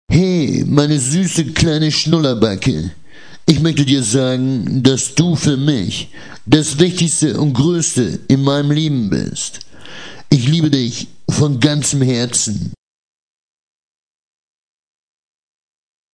Gagbag - sprechende Tüte